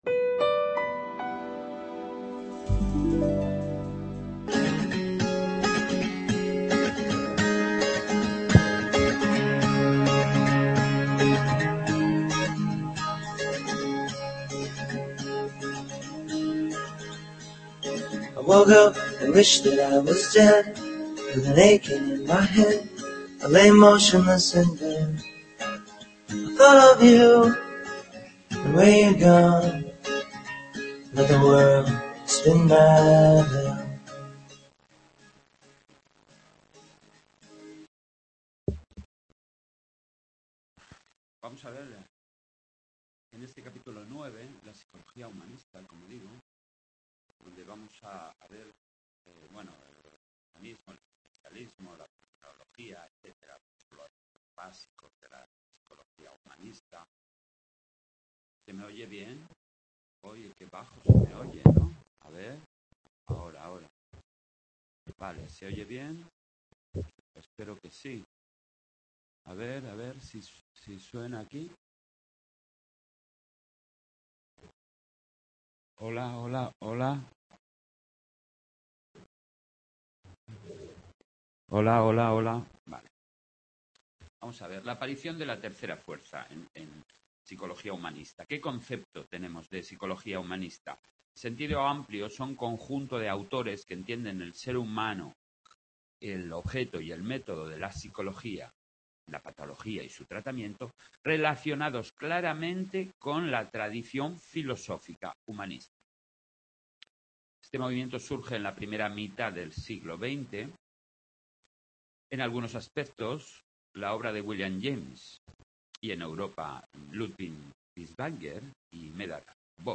Tema 9 de Psicología-CAD, Grabado en UNED Sant Boi